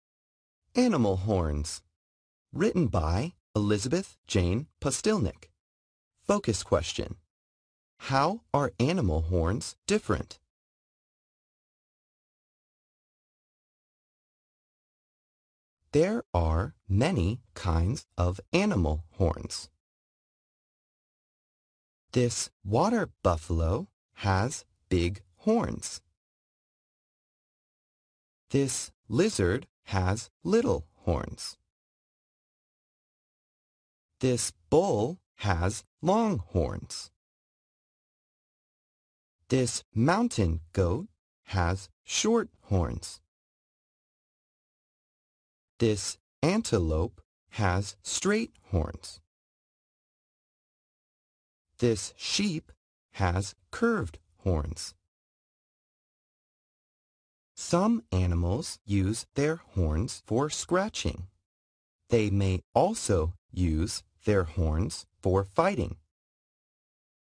Animal horns.mp3